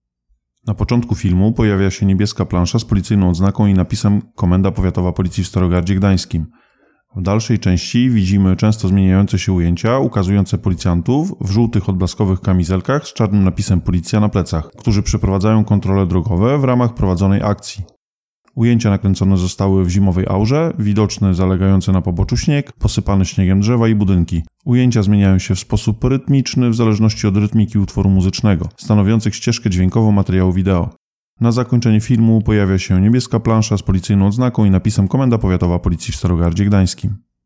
Nagranie audio Audio deskrypcja do materiału wideo